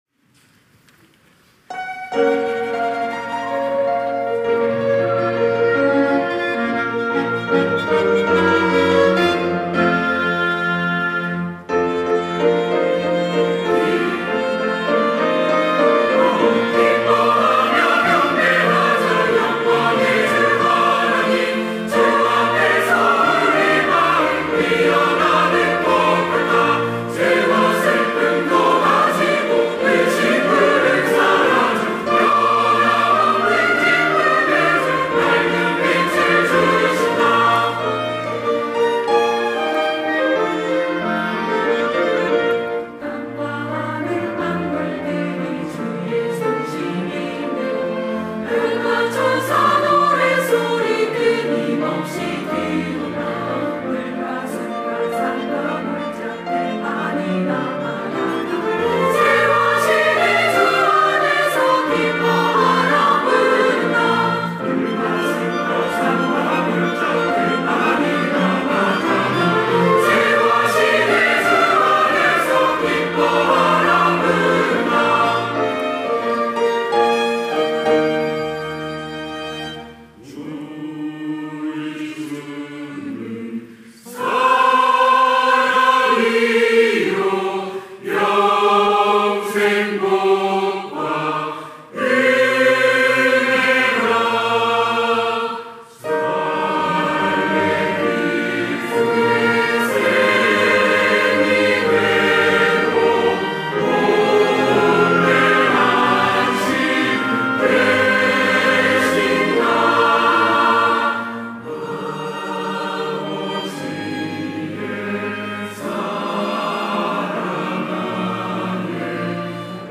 호산나(주일3부) - 기뻐하며 경배하자
찬양대 호산나